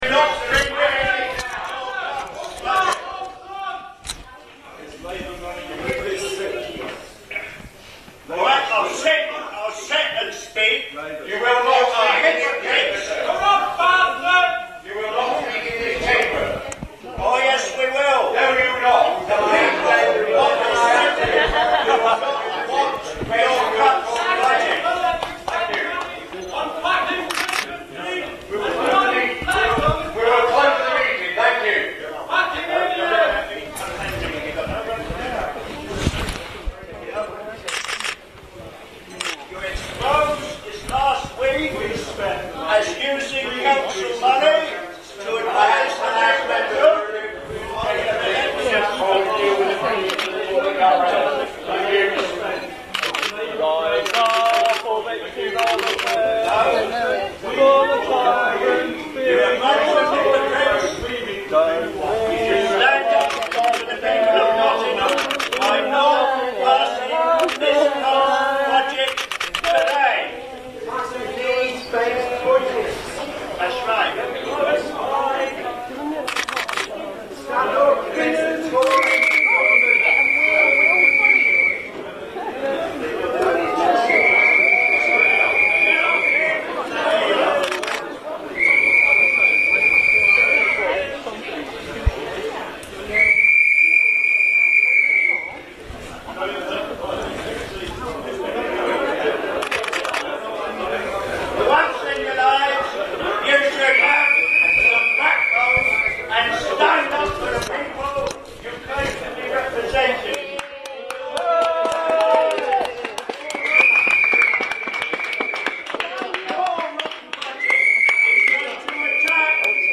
Nottingham Indymedia | Articles | Show | Nttm City Council Budget Meeting : Demo Inside